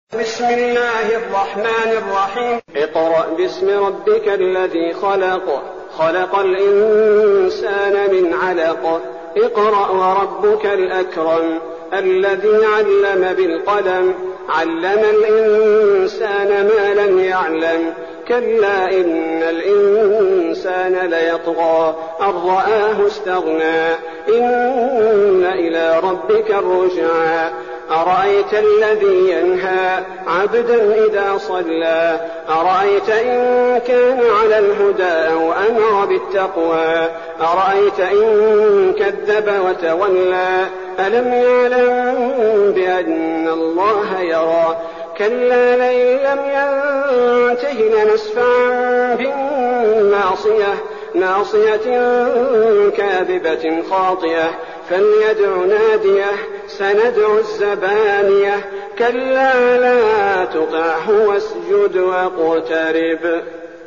المكان: المسجد النبوي الشيخ: فضيلة الشيخ عبدالباري الثبيتي فضيلة الشيخ عبدالباري الثبيتي العلق